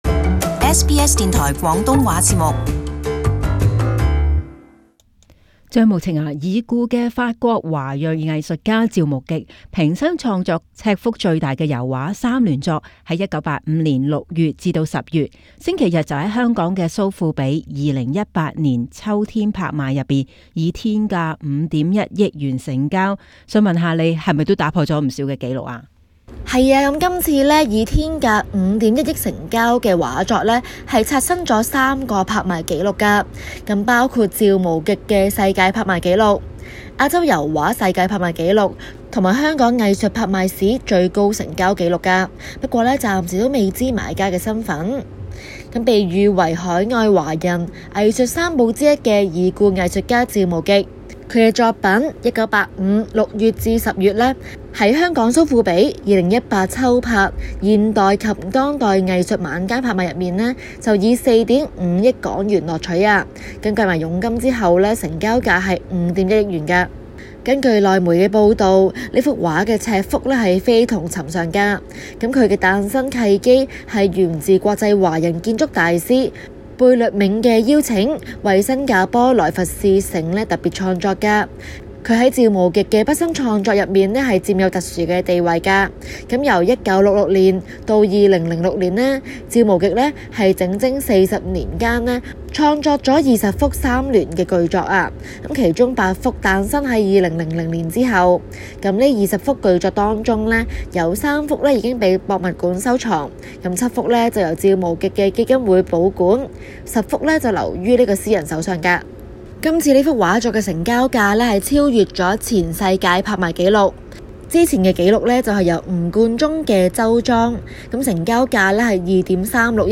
【中港快訊】法國華矞藝術家油畫天價成交